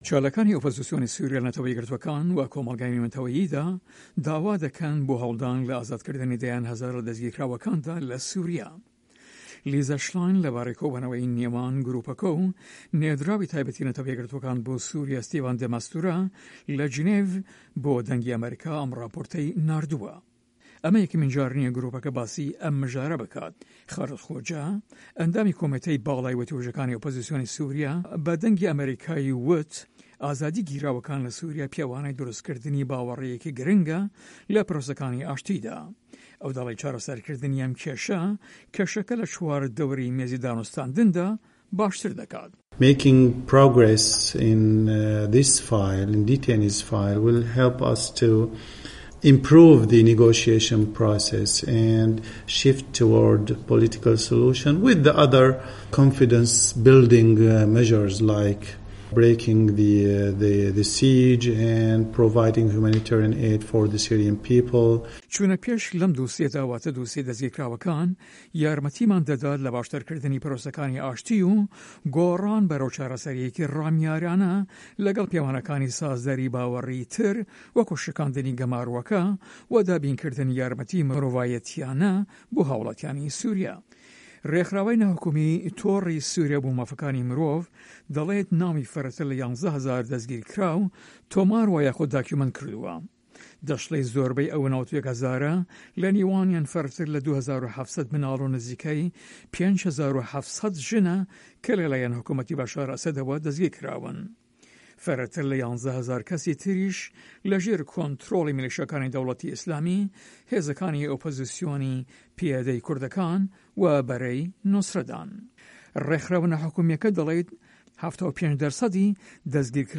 بۆ VOA ئەم راپۆرتەی ناردووە